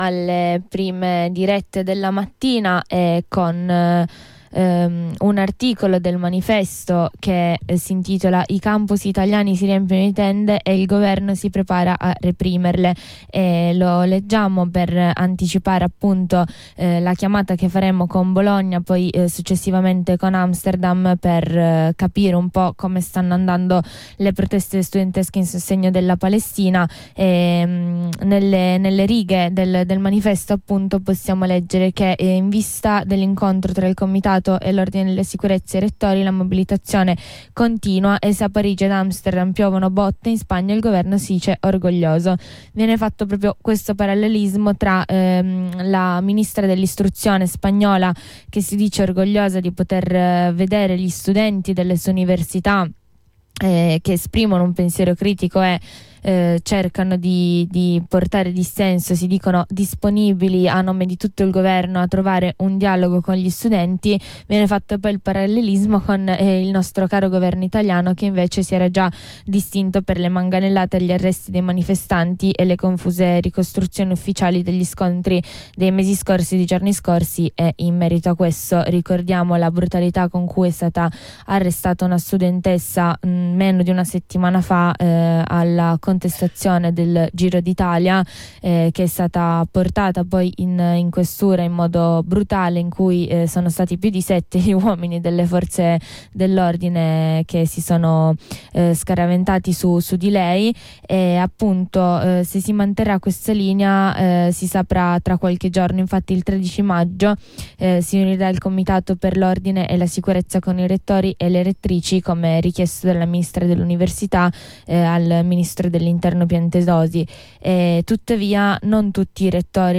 Da Bologna ad Amsterdam abbiamo parlato con chi sta partecipando a questo movimento.